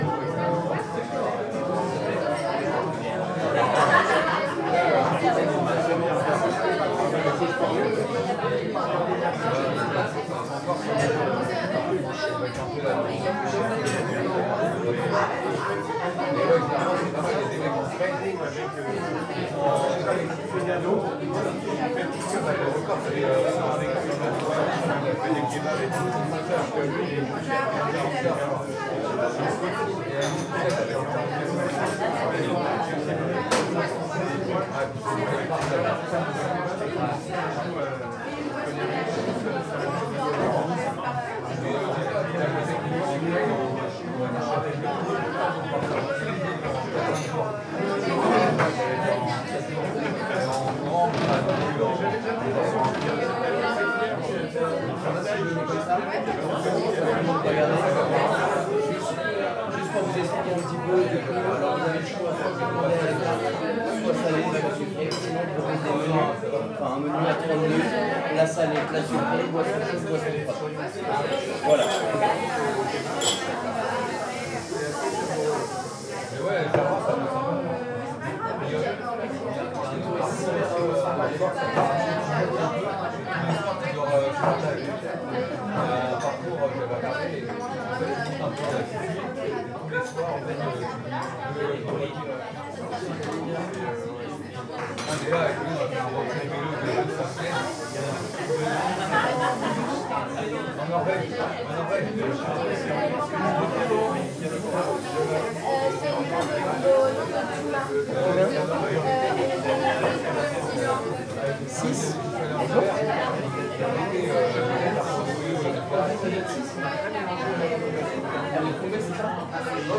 Restaurant #2
Ambiance sonore dans un restaurant, pendant le brunch. Environ 40 personnes.
Catégorie UCS : Ambiance / Restaurant & Bar (AMBRest)
Mode : Stéréophonique
Disposition des micros : ORTF
Conditions : Intérieur
Réalisme : Réel
Matériel : SoundDevices MixPre-3 + Neumann KM184